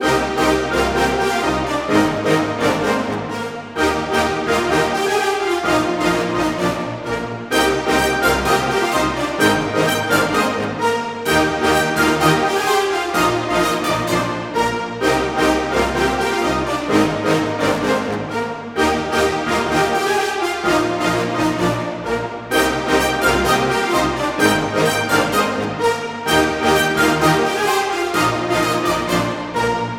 epic-orchestra-mixed_128bpm.wav